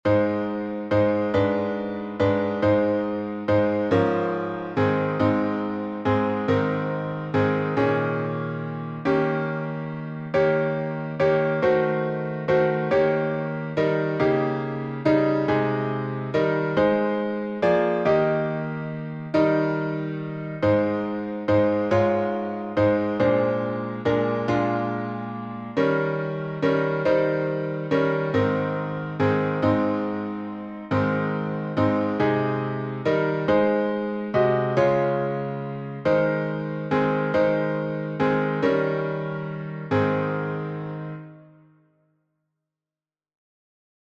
I Would Be Like Jesus — alternate harmonies.
Words by James Rowe (1865-1933), 1911Tune: SPRING HILL by Bentley D. Ackley (1872-1958)Key signature: A flat major (4 flats)Time signature: 6/4Meter: 8.6.8.6.(C.M.) with RefrainPublic Domain1.